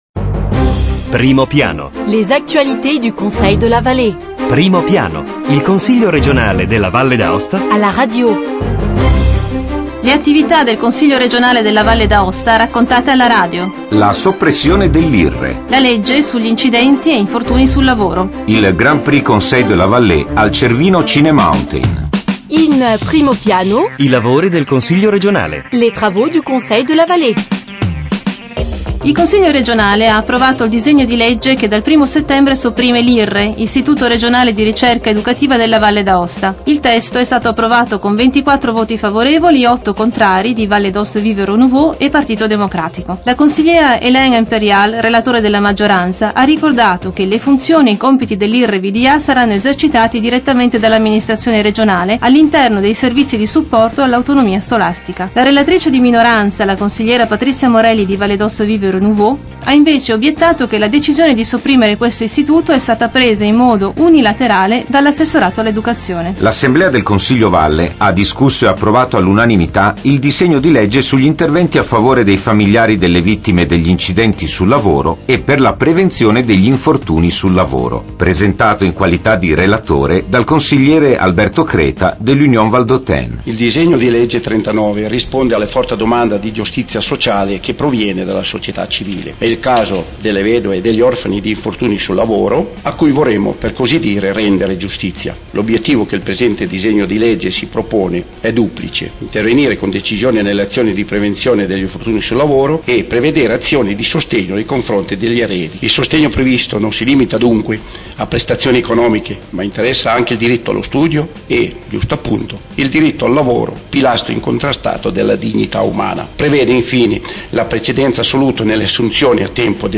Eventi e ricorrenze Documenti allegati 21 luglio 2009 Primo Piano Il Consiglio regionale alla radio: approfondimento settimanale sull'attivit� politica, istituzionale e culturale dell'Assemblea legislativa. Questa settimana, i lavori dell'ultima seduta del Consiglio regionale: l'approvazione della legge sulla soppressione dell'IRRE e della normativa sugli incidenti e infortuni sul lavoro, con le interviste al Consigliere relatore Alberto Cr�taz e al Presidente della V Commissione "Servizi sociali" Gianni Rigo.